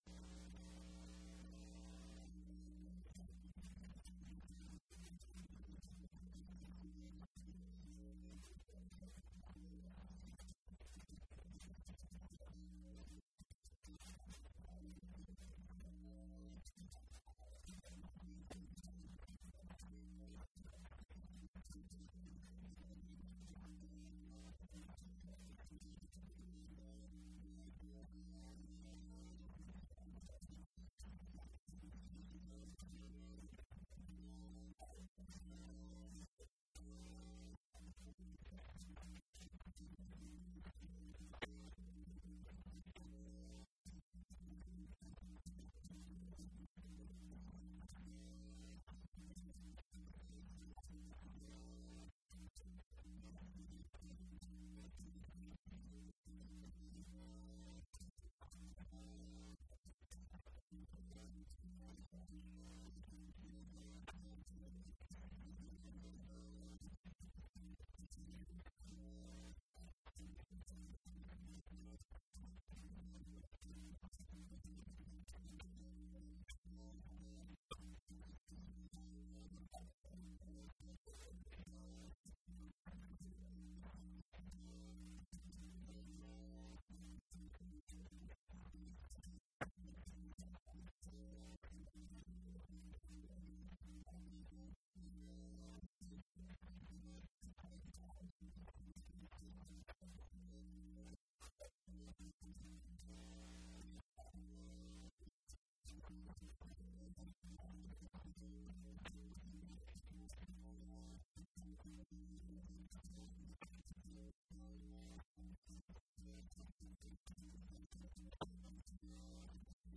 Colloquium Thursday, November 1, 2007, 3:30 pm EE-105 Abstract Everybody knows how to get a job at Microsoft.